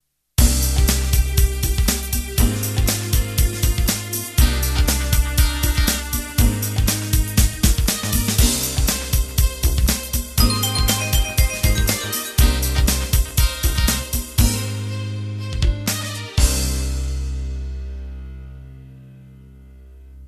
compatible MIDI file Intros and Endings